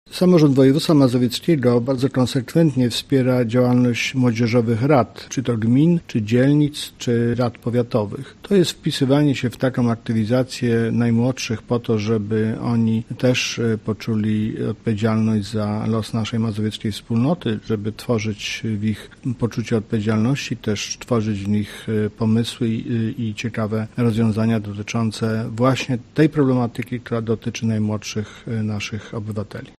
O wsparciu działań młodzieży mówi marszałek województwa mazowieckiego, Adam Struzik: